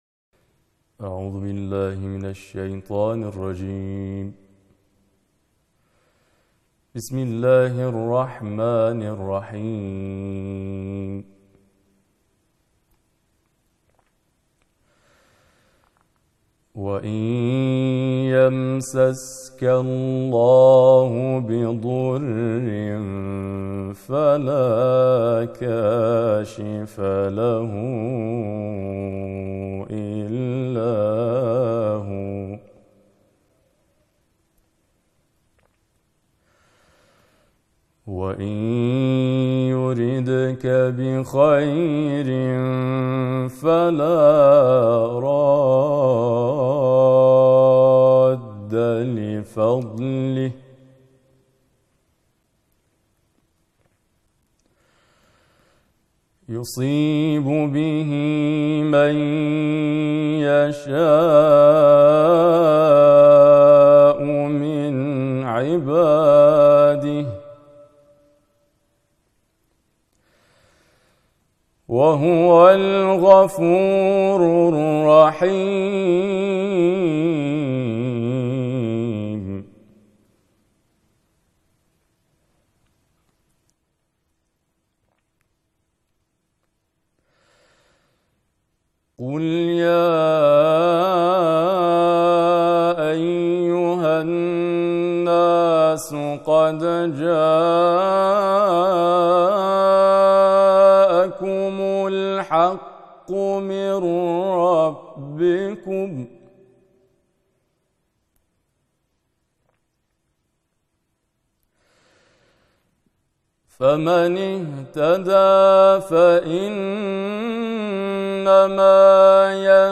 به گزارش ایکنا، همزمان با ایام ماه مبارک رمضان، طرح ۳۰ کرسی تلاوت قرآن در ۳۰ شب ماه بهار قرآن به صورت مجازی و با حضور قاریان ممتاز و بین‌المللی از سوی فرهنگ‌سرای قرآن اجرا شد.